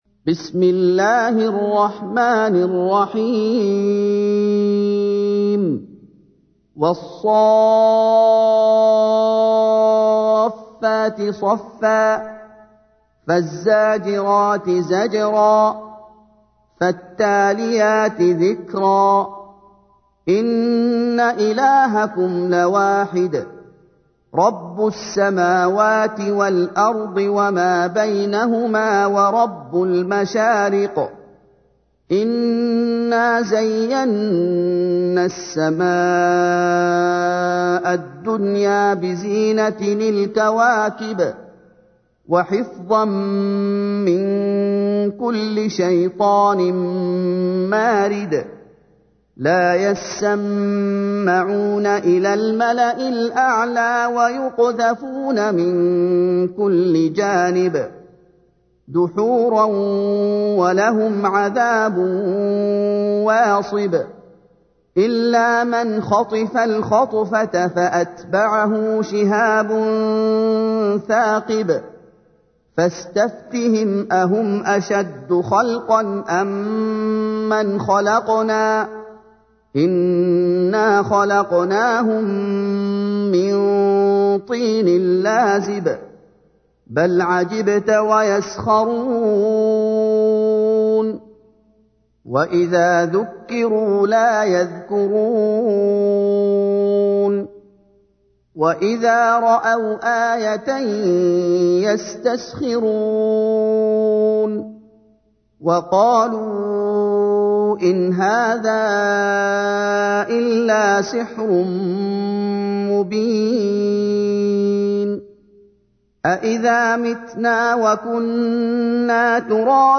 تحميل : 37. سورة الصافات / القارئ محمد أيوب / القرآن الكريم / موقع يا حسين